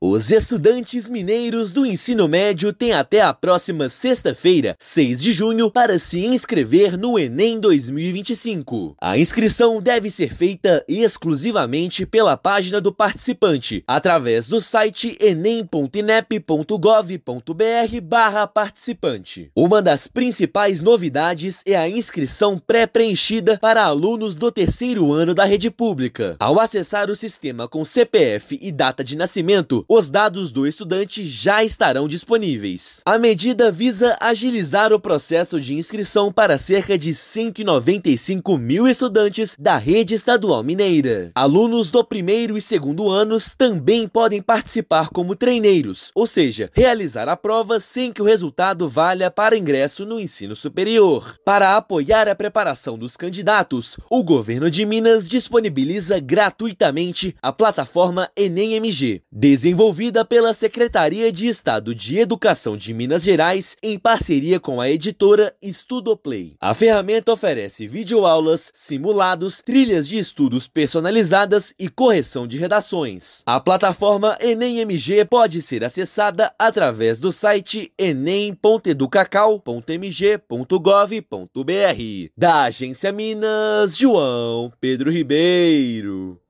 [RÁDIO] Estudantes do ensino médio da rede estadual têm até sexta-feira (6/6) para se inscrever no Enem 2025
Exame oferece acesso ao ensino superior com novidades neste ano; Governo de Minas garante preparação gratuita pela plataforma Enem MG. Ouça matéria de rádio.